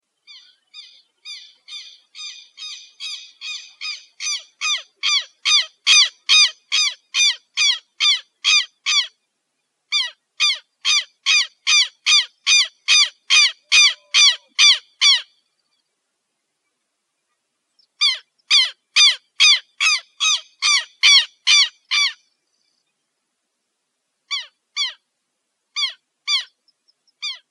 tero